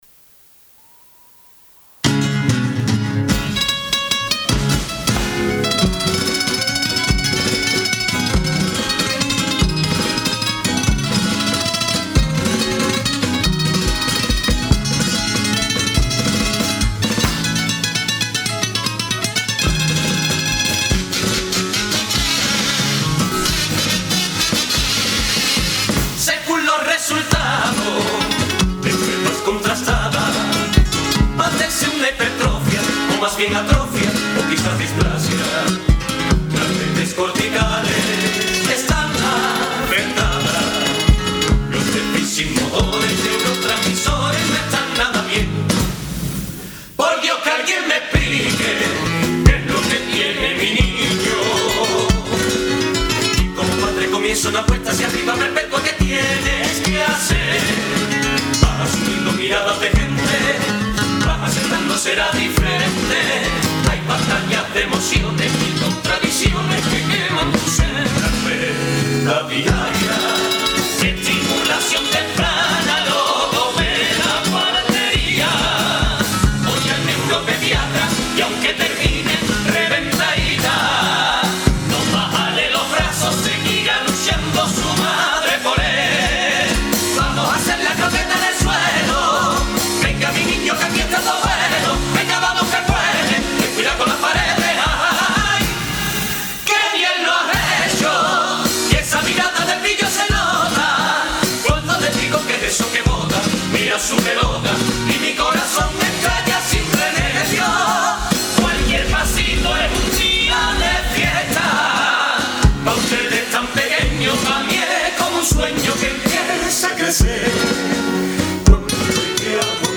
La comparsa de adultos ‘Los Pacientes’ se ha proclamado ganadora de la XXVIII edición del Concurso ‘Fermín Salvochea’ de la ONCE, que premian las letras más solidarias y comprometidas del Carnaval de Cádiz, por su letra